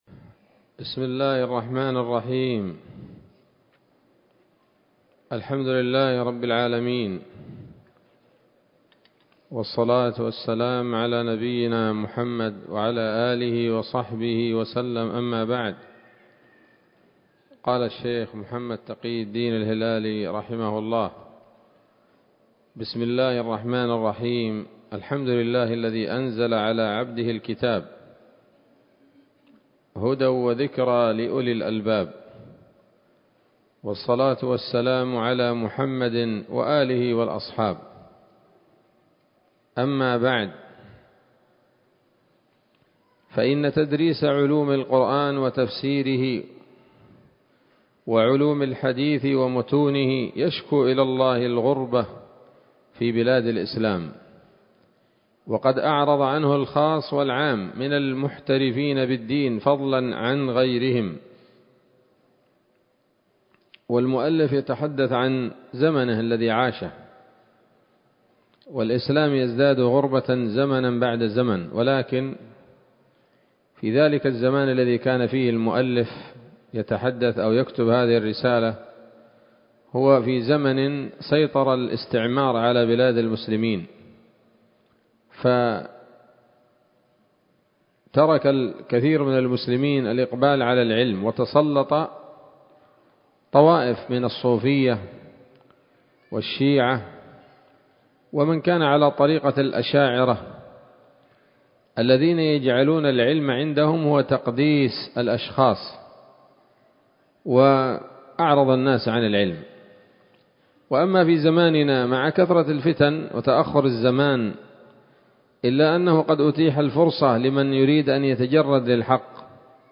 الدرس الثاني من كتاب نبذة من علوم القرآن لـ محمد تقي الدين الهلالي رحمه الله